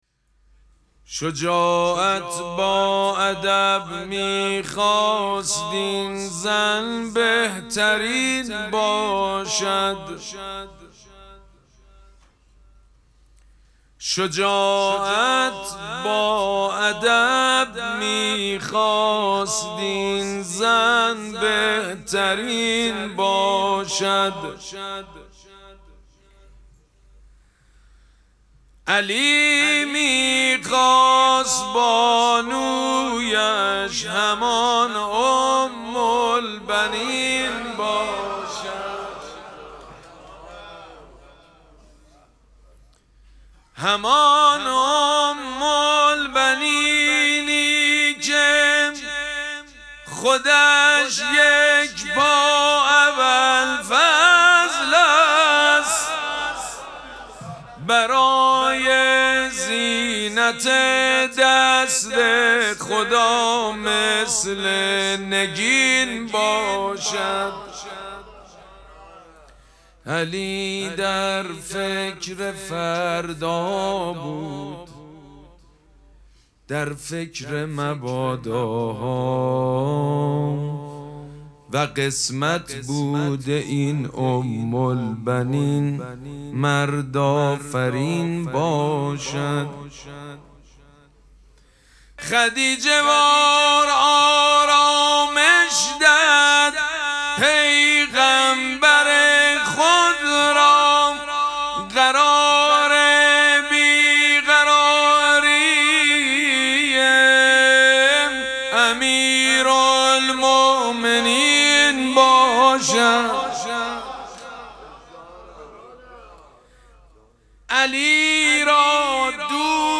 مراسم مناجات شب نهم ماه مبارک رمضان
حسینیه ریحانه الحسین سلام الله علیها
مدح
حاج سید مجید بنی فاطمه